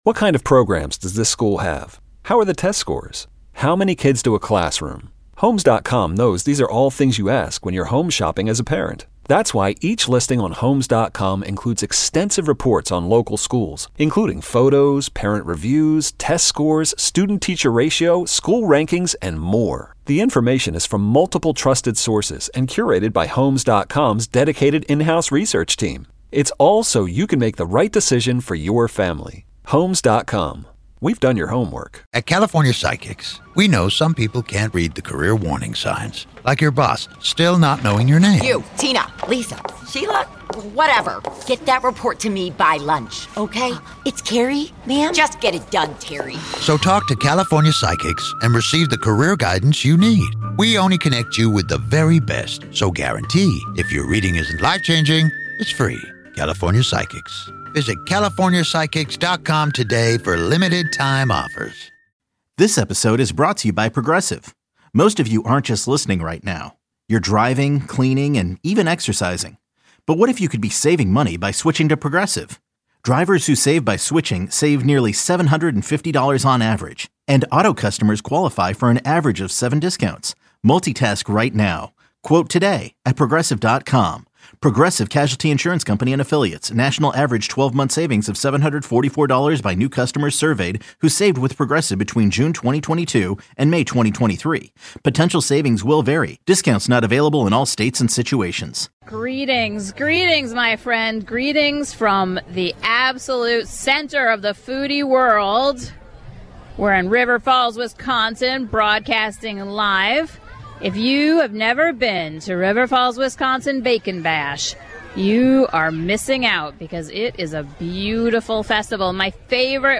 Wisconsin state legislator and Belle Vinez Winery owner Shannon Zimmerman